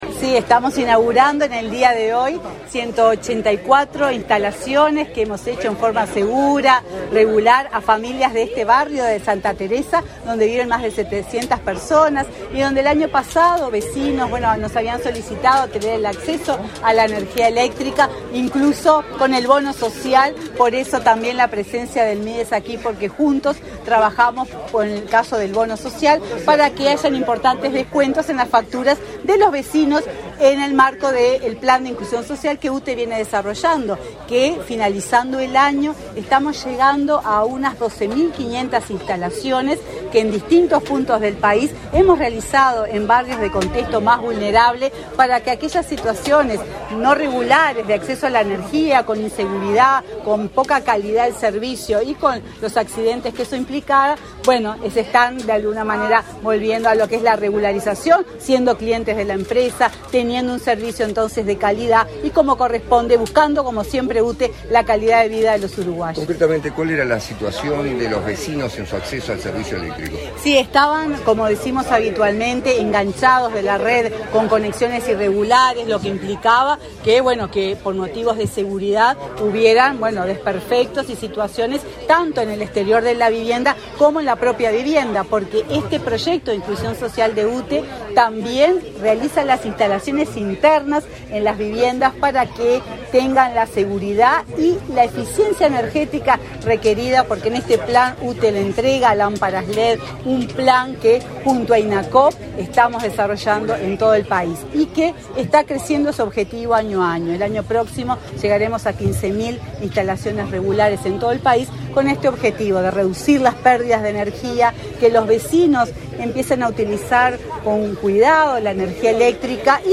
Declaraciones a la prensa de la presidenta de UTE, Silvia Emaldi
Declaraciones a la prensa de la presidenta de UTE, Silvia Emaldi 09/12/2022 Compartir Facebook X Copiar enlace WhatsApp LinkedIn Tras participar en la inauguración de obras de electrificación en el barrio Santa Teresa, en Montevideo, este 9 de diciembre, la presidenta de la empresa estatal realizó declaraciones a la prensa.